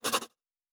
Writing 6.wav